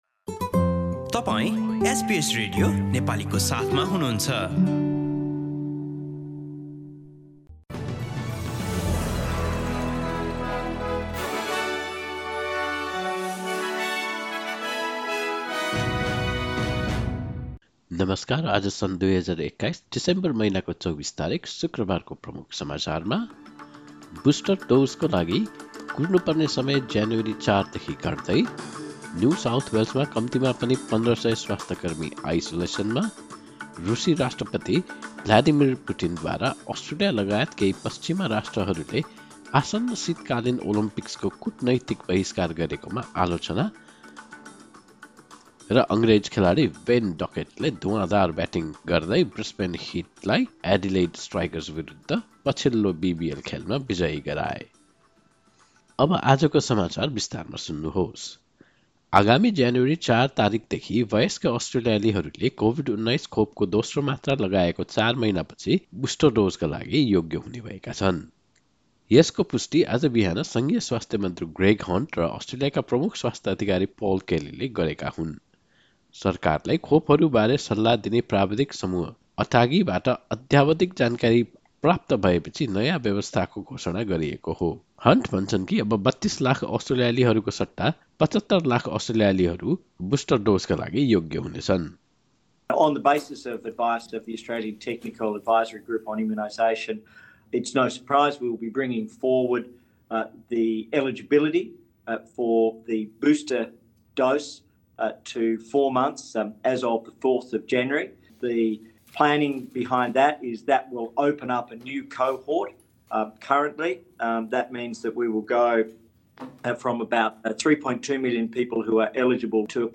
एसबीएस नेपाली अस्ट्रेलिया समाचार: शुक्रवार २४ डिसेम्बर २०२१